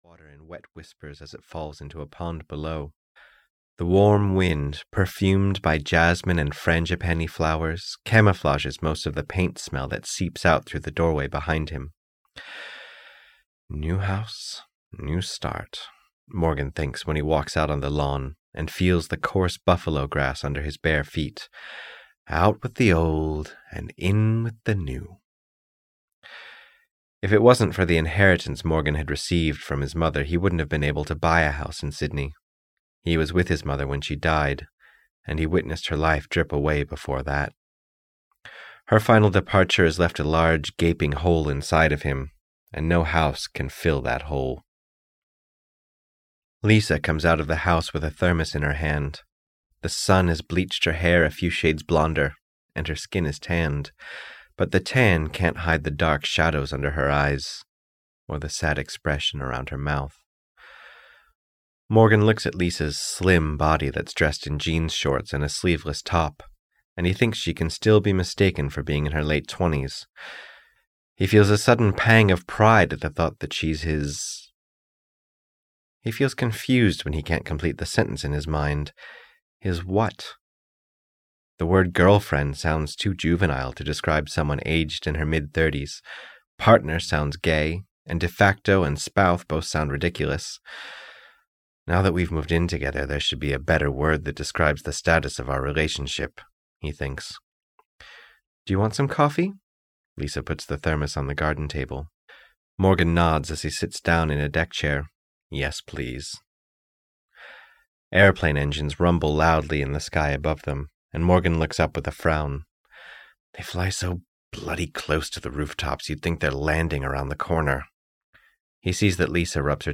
GONE (EN) audiokniha
Ukázka z knihy